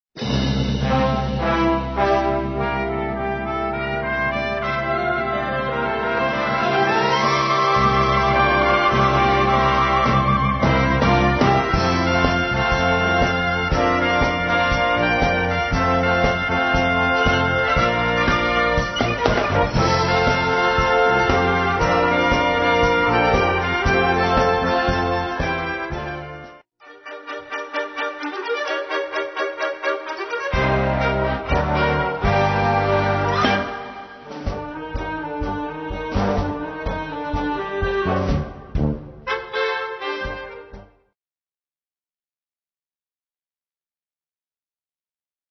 Gattung: Medley
Besetzung: Blasorchester